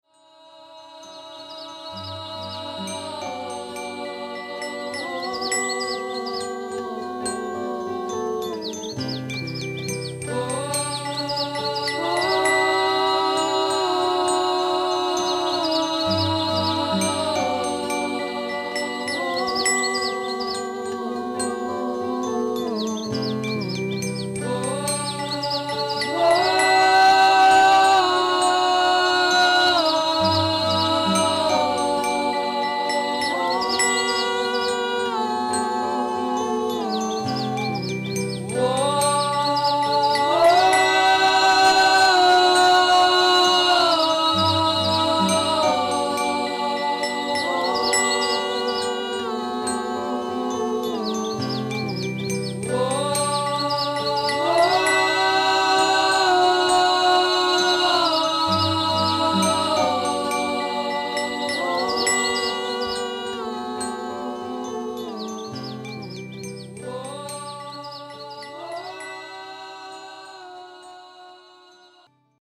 Some of the jazz form this session sounded OK.